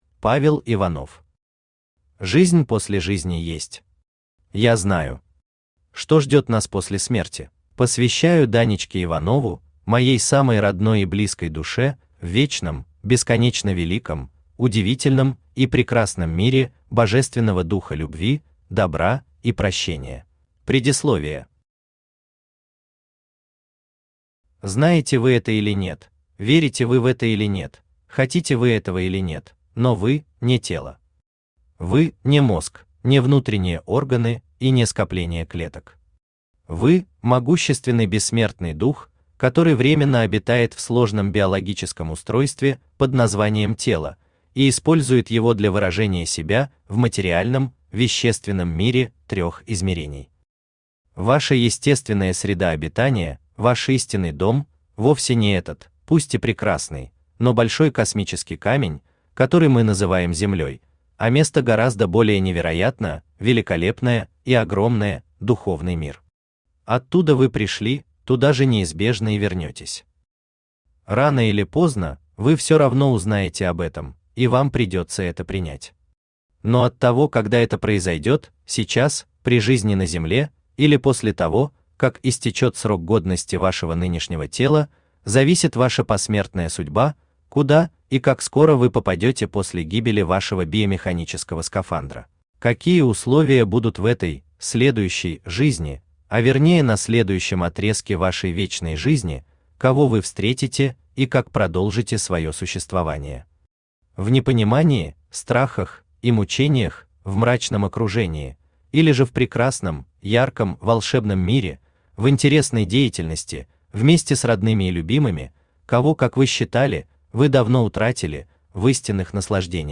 Аудиокнига Жизнь после жизни есть. Я знаю! Что ждет нас после смерти?
Автор Павел Иванов Читает аудиокнигу Авточтец ЛитРес.